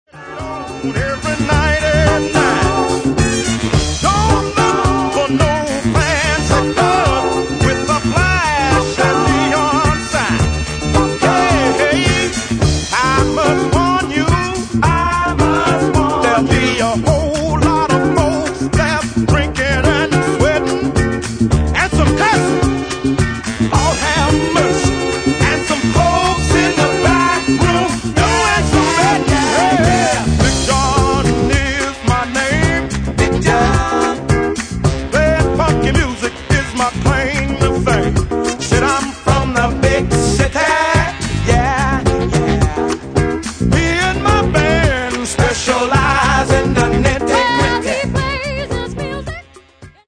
Genere:   Soul Funky